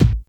Kick_46.wav